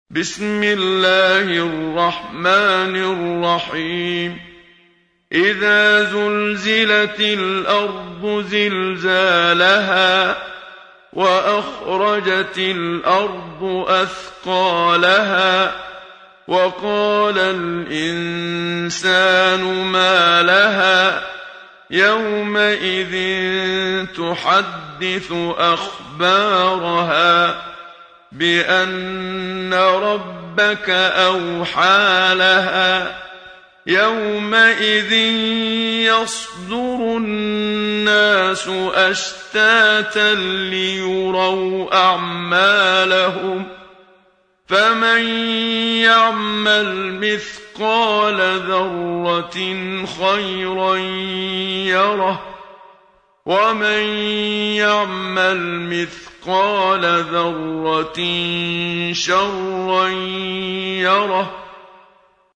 سورة الزلزلة | القارئ محمد صديق المنشاوي